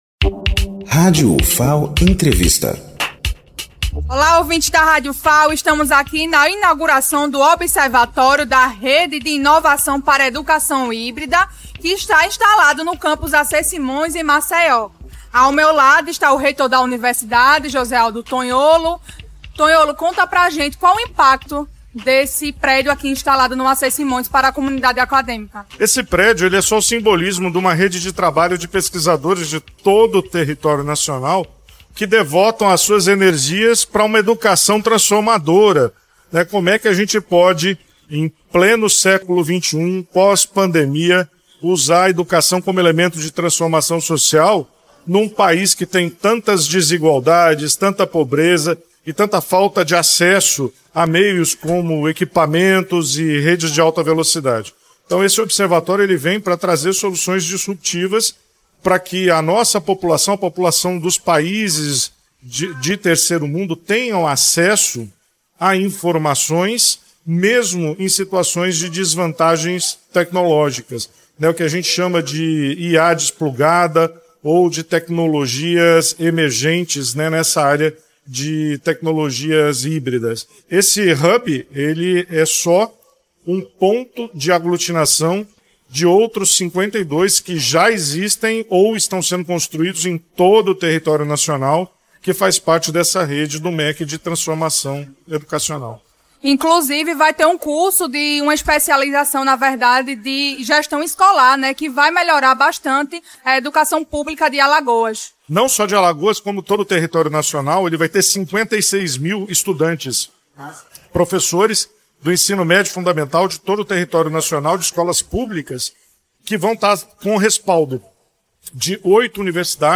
Entrevista com Josealdo Tonholo, reitor da Ufal.